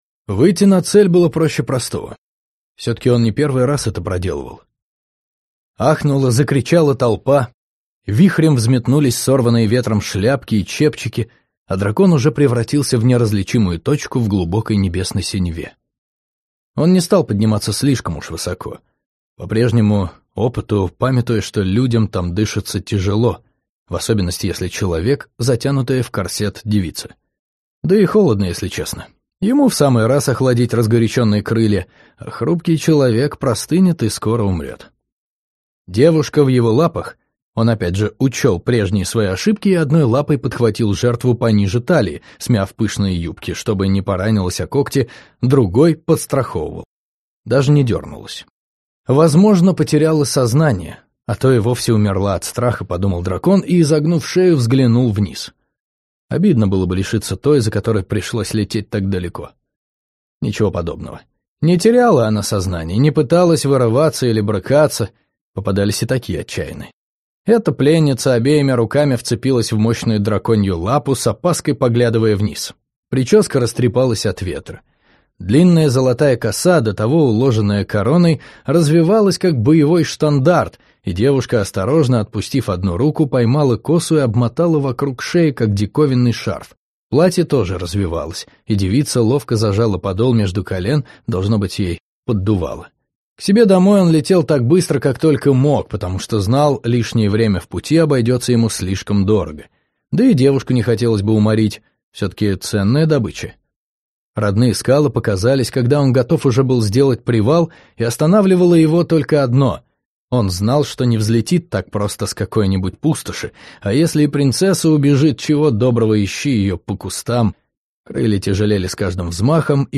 Аудиокнига Дракон, который не любил летать | Библиотека аудиокниг